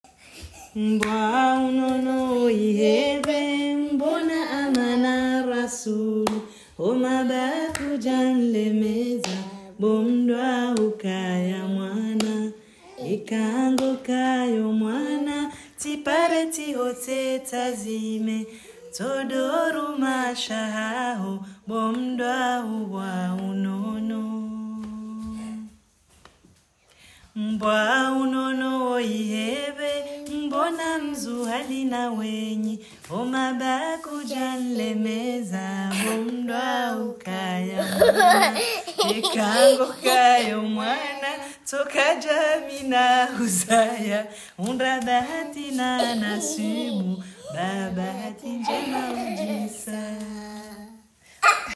Amana rasulu (berceuse)